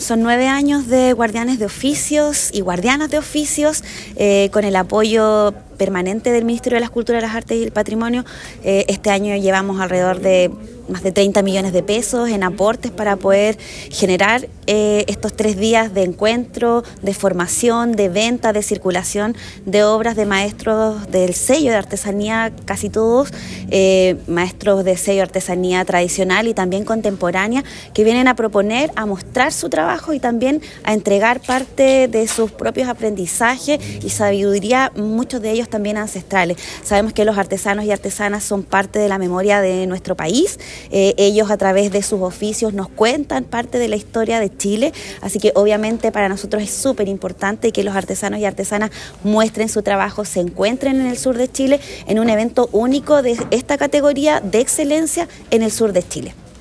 Este lunes, en la sala de sesiones del municipio de Osorno, se realizó el lanzamiento oficial de “Guardianes de Oficios”, una iniciativa que tendrá lugar los días 7, 8 y 9 de noviembre en el Centro Cultural de Osorno.
La Seremi de las Culturas, las Artes y el Patrimonio, Cristina Añasco, subrayó la importancia de este proyecto, que en su novena versión se ha consolidado como una plataforma fundamental para visibilizar el trabajo de los artesanos y artesanas locales.